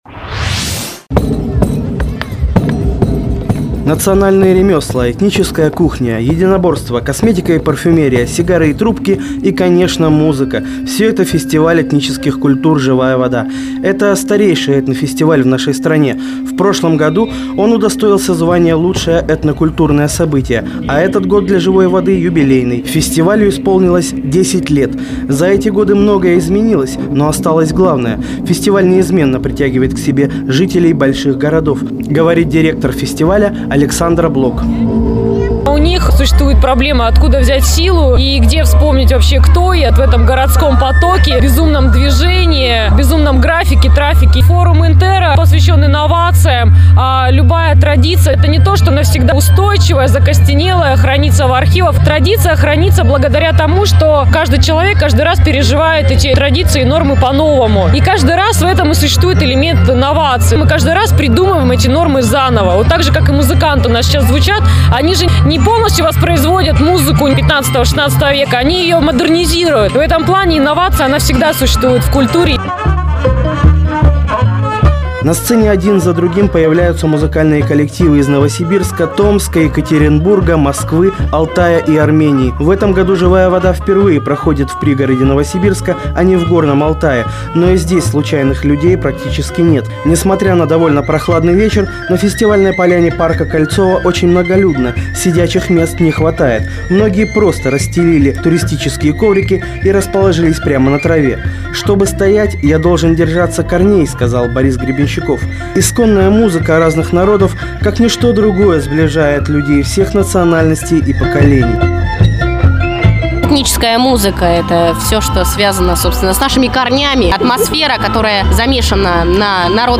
Фестиваль "Живая вода". 11 сентября 2009 г., Кольцово
Мои репортажи, вышедшие в эфир Радио "Городская волна"